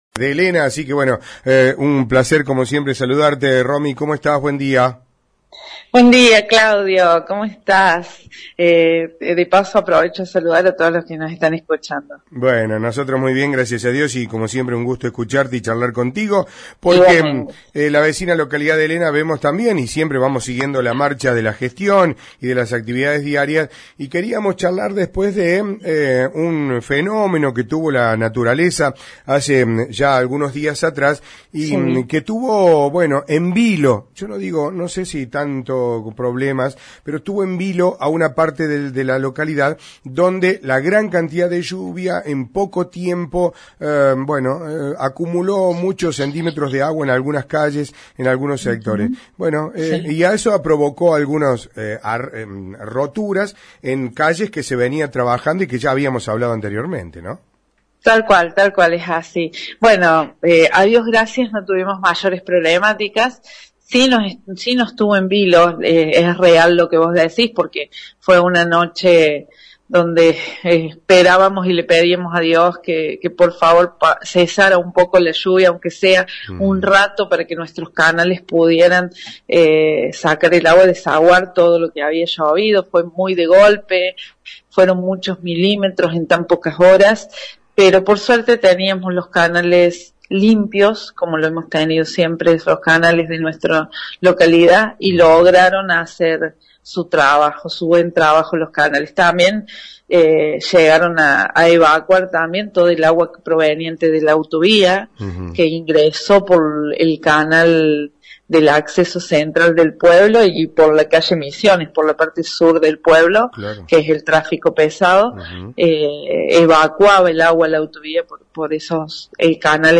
En la mañana de hoy hablamos con la intendente de la localidad de Elena, Romina Aguirre, quien hizo un repaso de todas las actividades que vienen desarrollando desde el ejecutivo municipal.